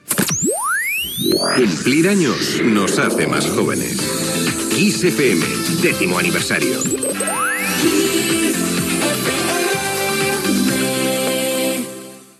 Indicatiu dels 10 anys de l'emissora